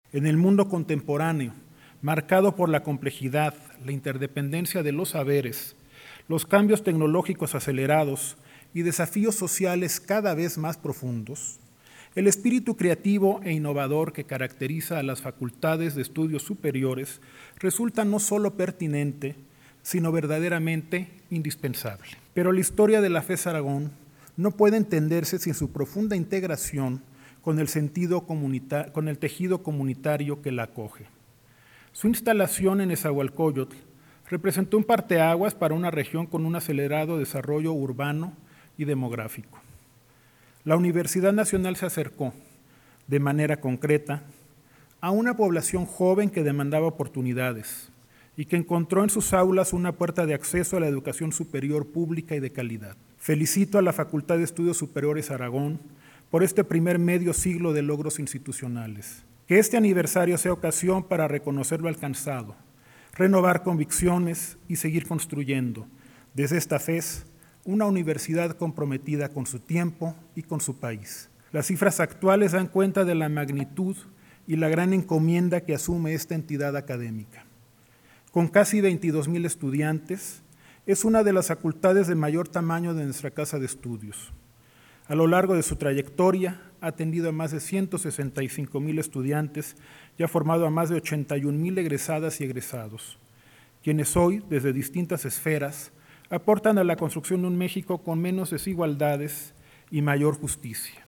PALABRAS RECTOR 50 ANIVERARIO FES ARAGON